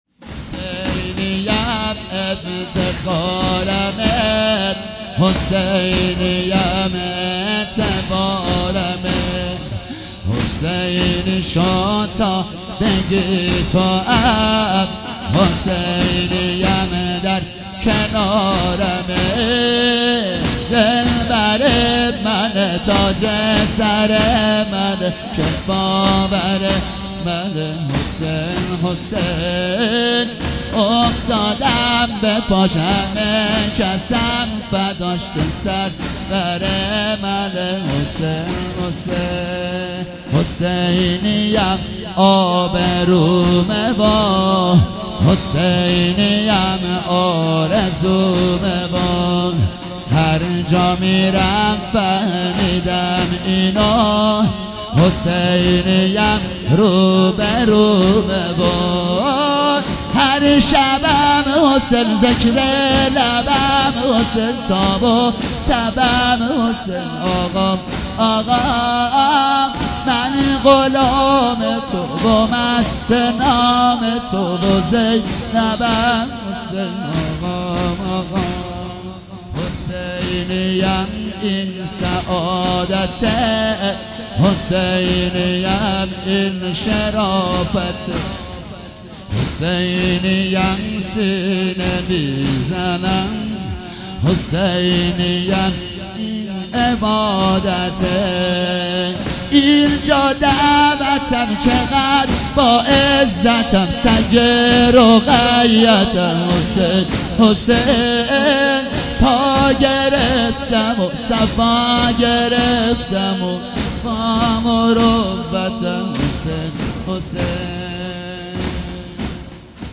شب پنجم محرم96 - زنجیرزنی - حسینیم افتخارمه
مداحی